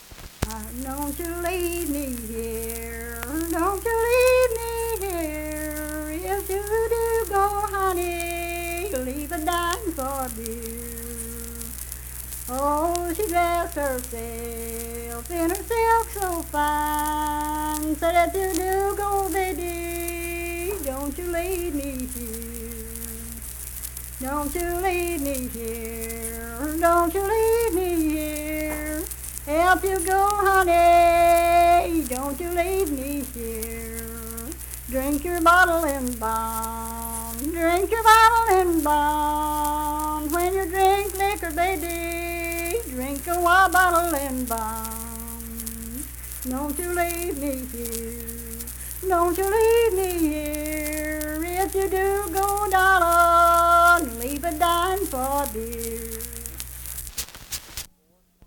Unaccompanied vocal music
Voice (sung)
Kirk (W. Va.), Mingo County (W. Va.)